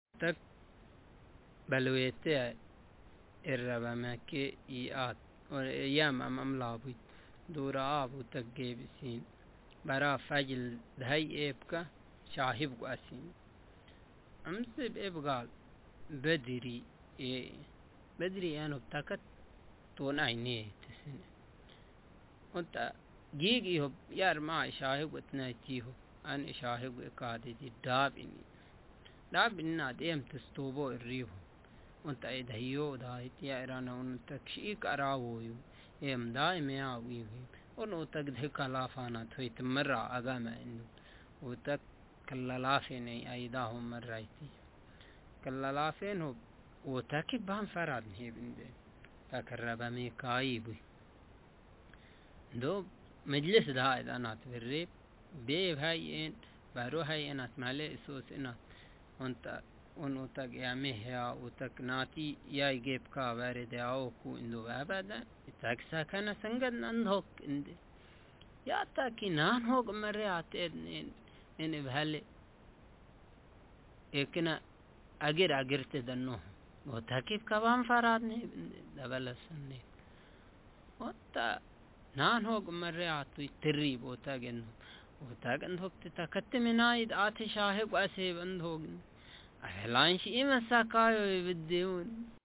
Speaker sexm
Text genrepersonal narrative